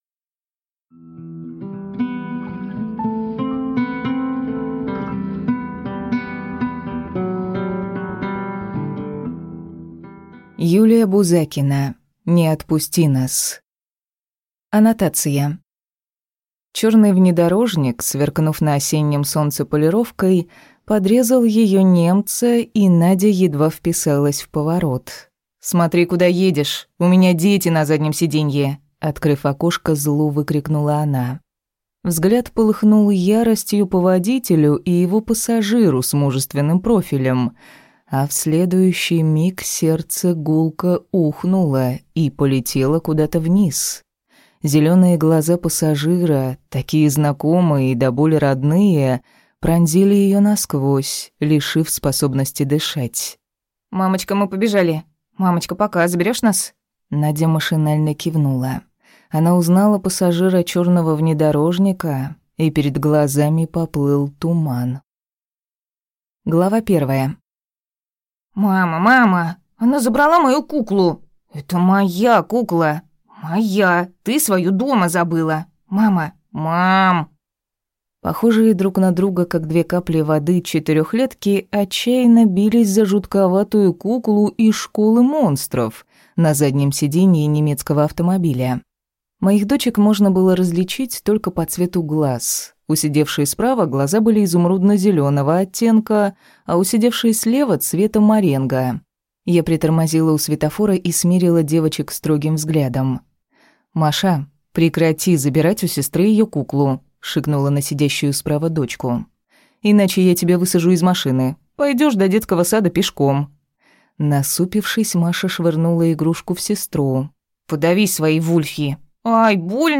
Аудиокнига Не отпусти нас | Библиотека аудиокниг